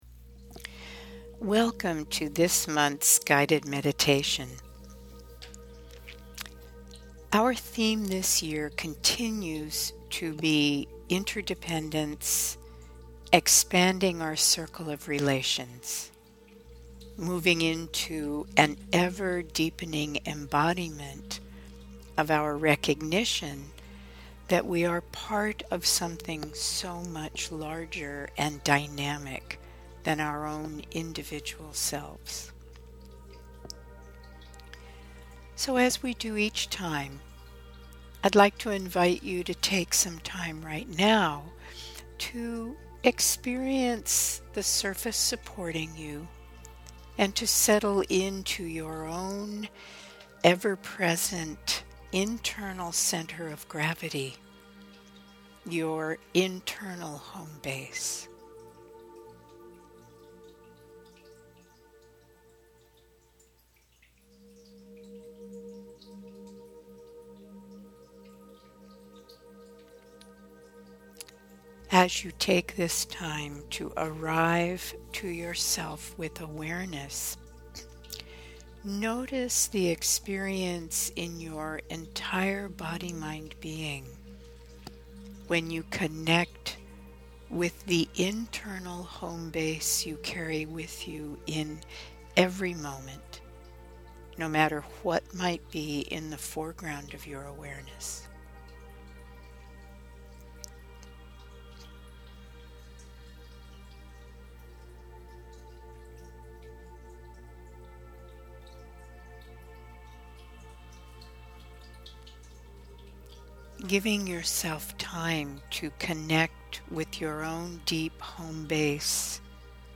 July 2019 Audio Meditation